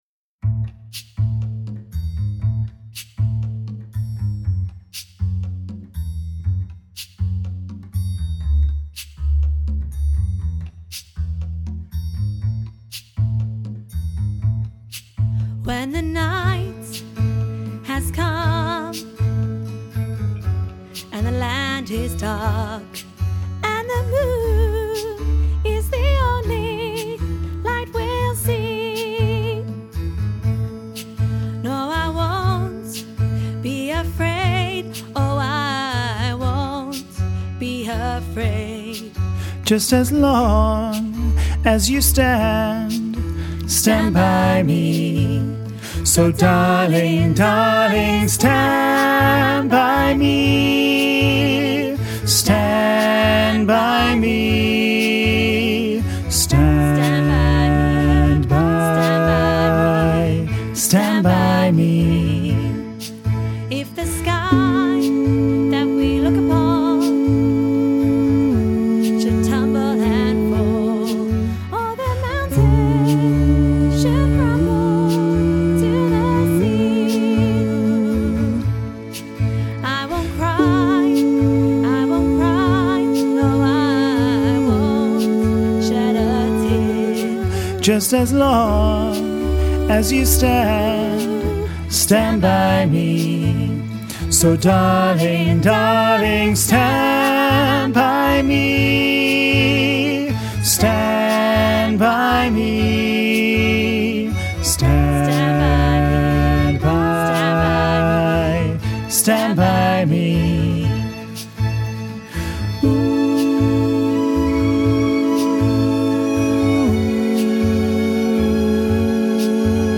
Listen to bass track with soprano and alto accompaniment
stand-by-me-bass-half-mix.mp3